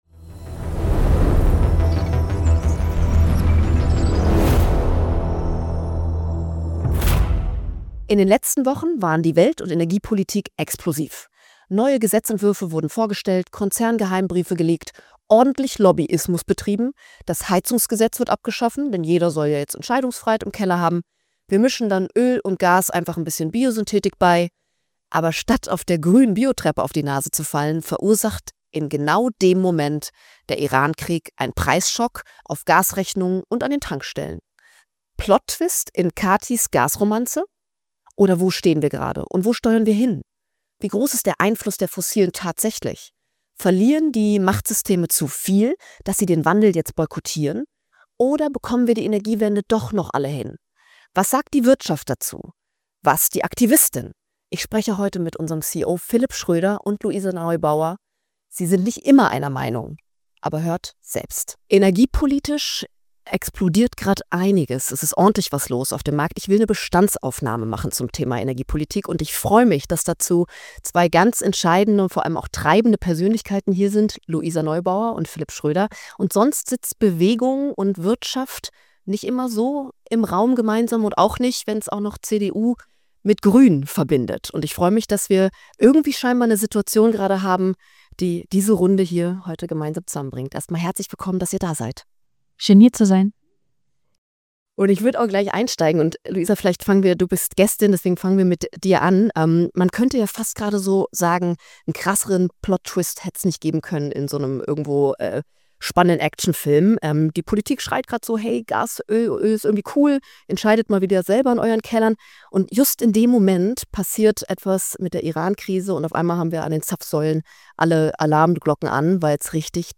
Eine grüne Klimaaktivistin.